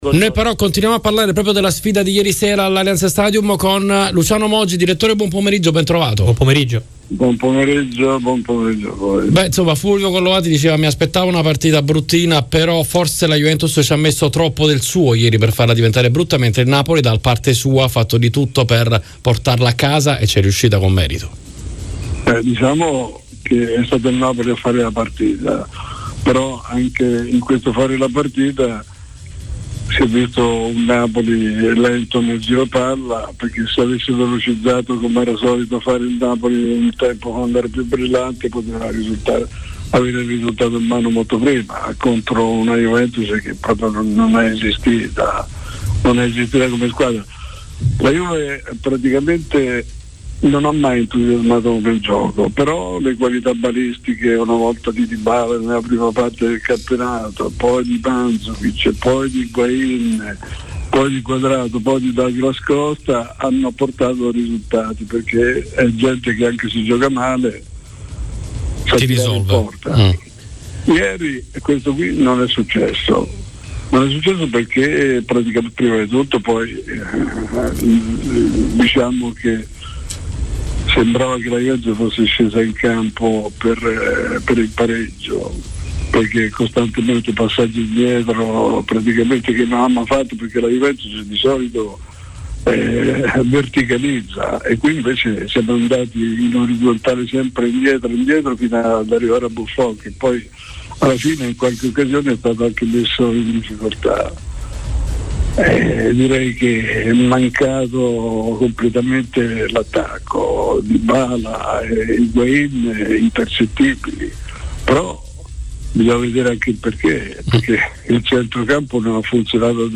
Intervenuto nel corso di 'Maracanà', trasmissione pomeridiana di 'RMC Sport, l'ex direttore generale della Juventus, Luciano Moggi, si è soffermato sulla lotta Scudetto tra i bianconeri e il Napoli: